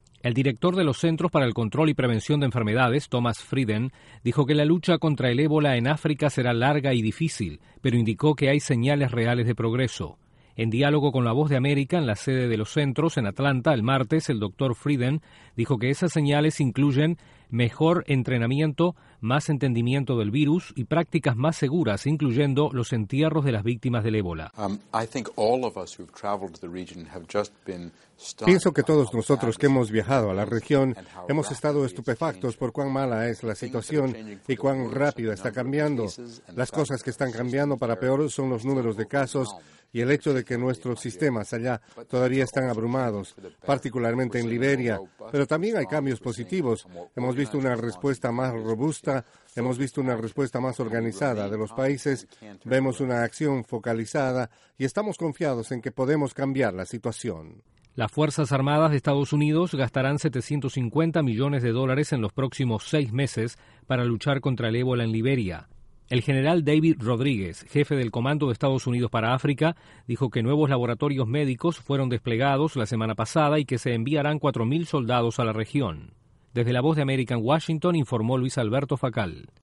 Un alto funcionario de salud de Estados Unidos prevé una larga y difícil lucha contra el ébola en África. Desde la Voz de América en Washington informa